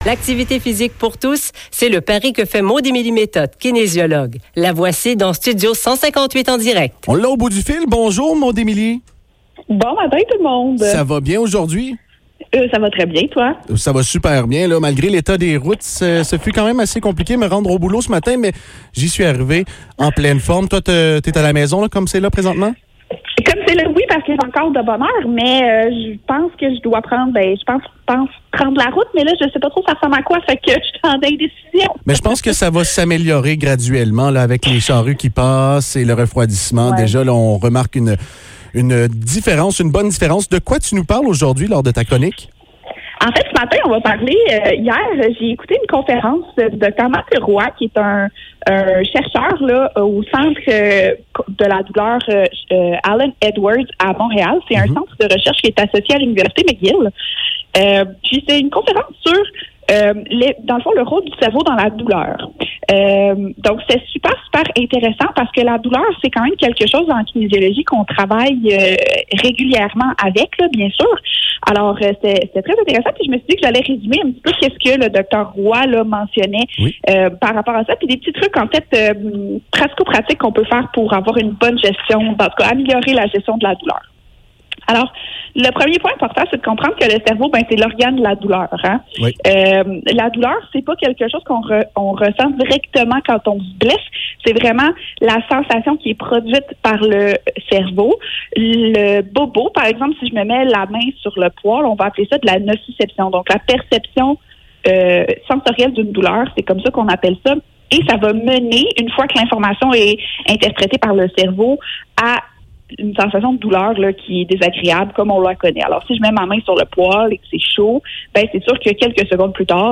Chronique